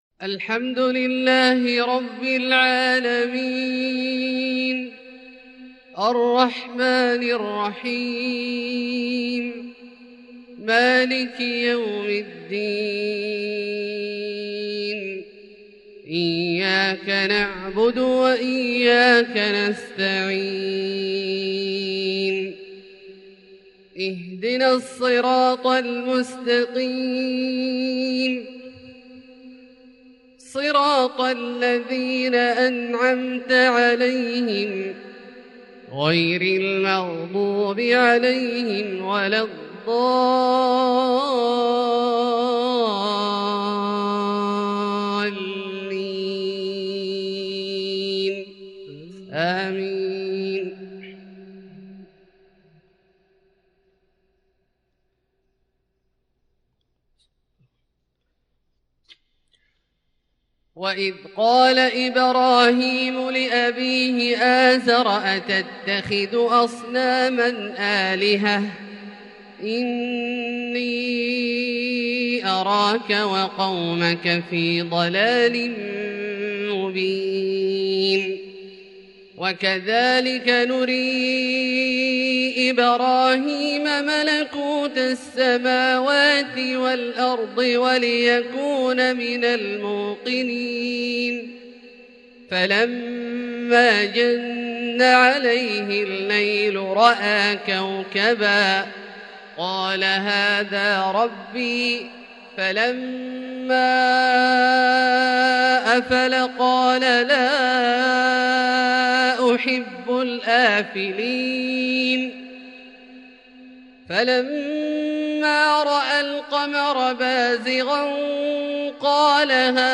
فجر الأثنين 5-6-1442 هــ من سورة الانعام | Fajr prayer from Surat Al-An'aam 18/1/2021 > 1442 🕋 > الفروض - تلاوات الحرمين